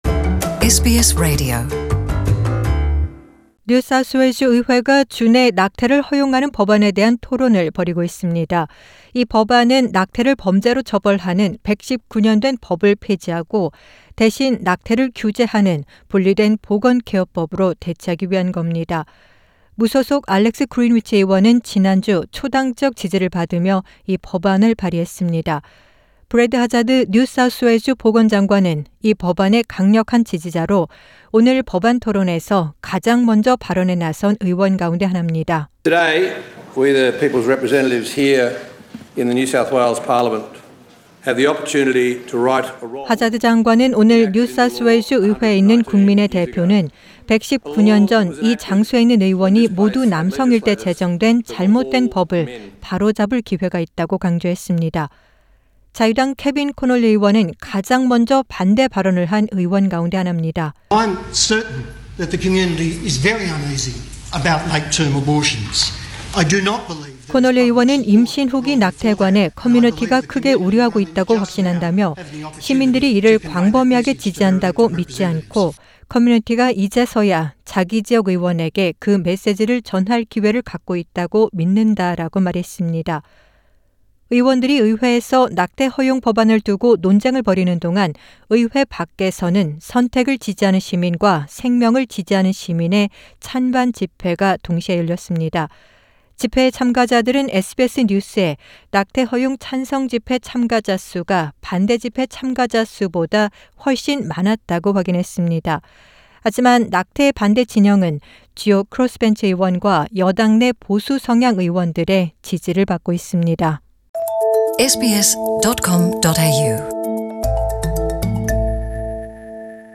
NSW Minister for Health and Medical Research Brad Hazzard speaks during debate.